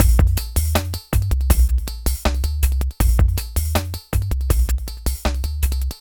Downtempo 24.wav